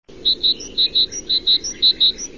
Звуки синицы
Пение синицы в естественной среде